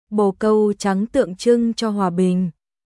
Bồ câu trắng tượng trưng cho hòa bình.白い鳩は平和の象徴です。ボー カウ チャン トゥオン チュン チョー ホア ビン